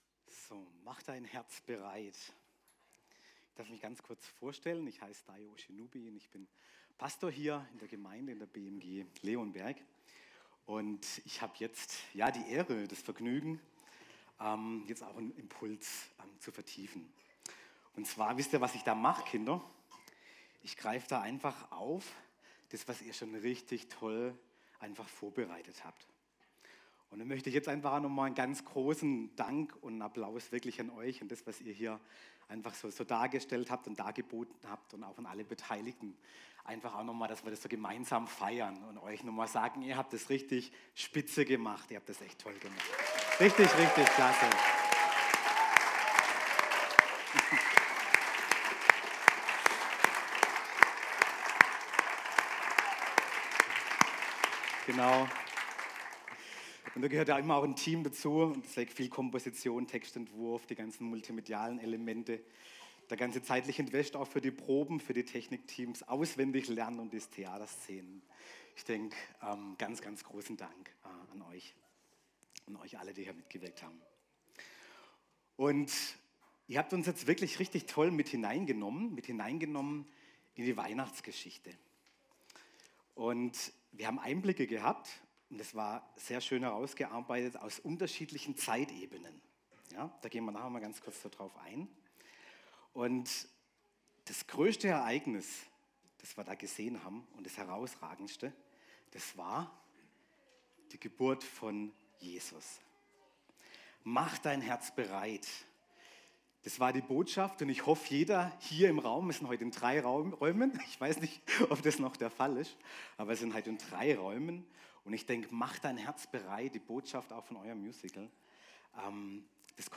Kategorie Predigten